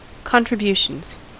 Ääntäminen
Ääntäminen US : IPA : [ˌkɒn.tɹɪ.ˈbju.ʃənz] Tuntematon aksentti: IPA : /ˌkɒn.tɹɪ.ˈbjuː.ʃənz/ Haettu sana löytyi näillä lähdekielillä: englanti Contributions on sanan contribution monikko.